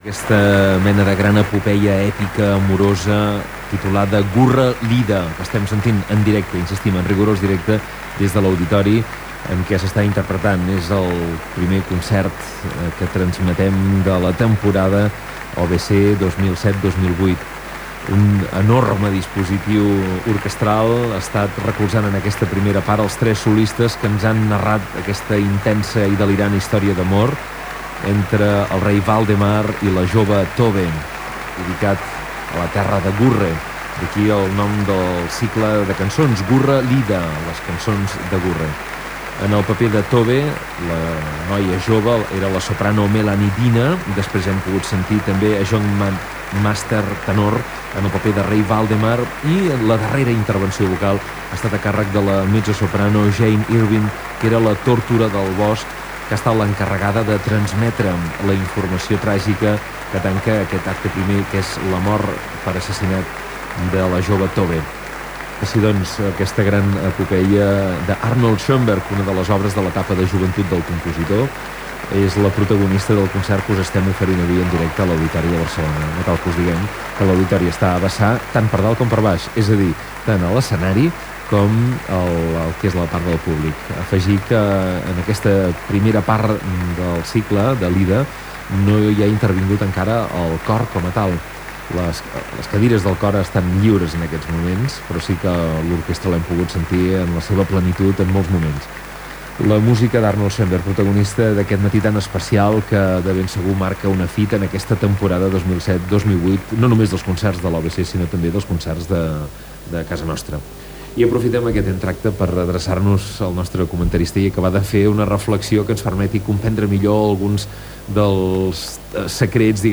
Transmissió del primer concert de l'OBC 2007-2008 des de l'Auditori de Barcelona.
Musical